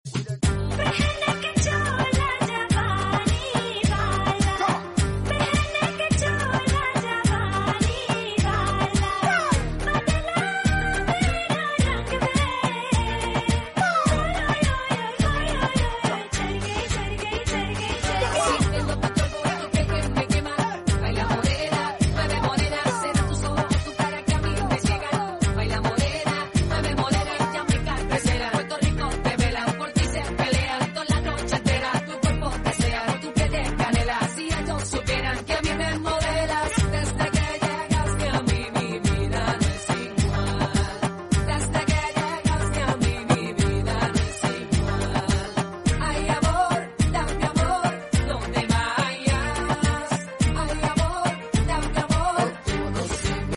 Toyota Probox Boot Speakers Sound Effects Free Download